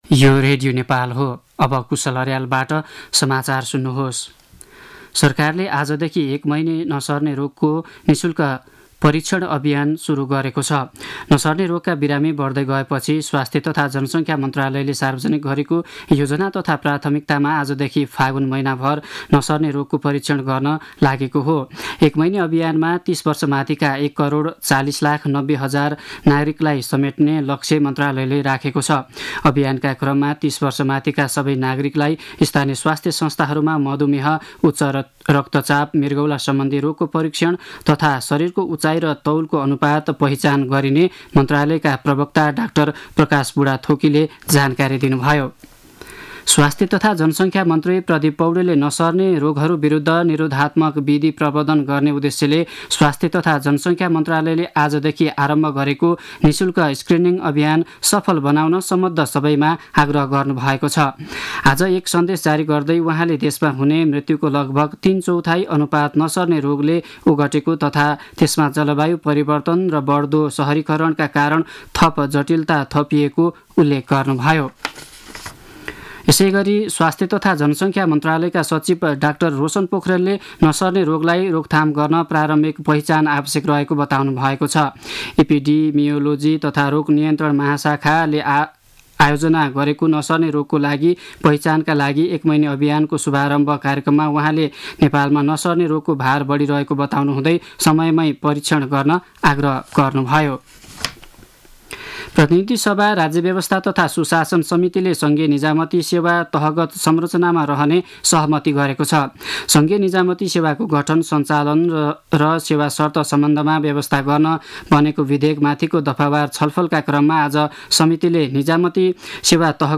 दिउँसो ४ बजेको नेपाली समाचार : २ फागुन , २०८१